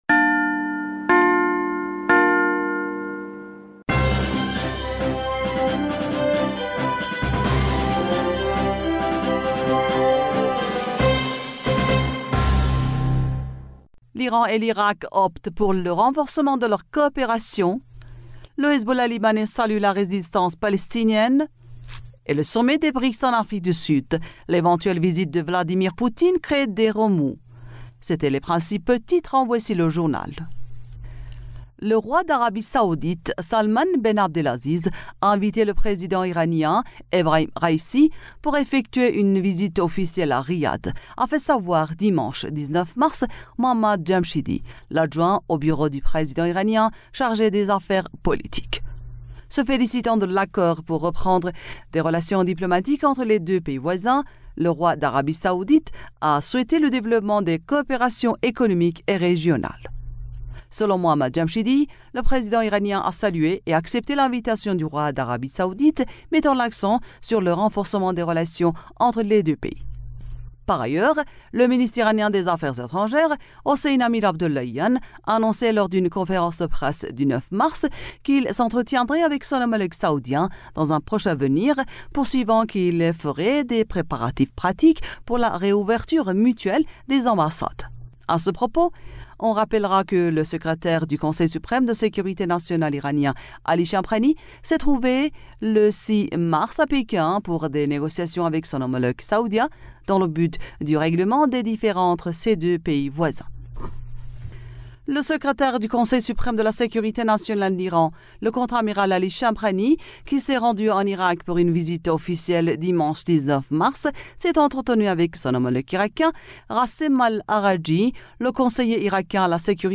Bulletin d'information du 20Mars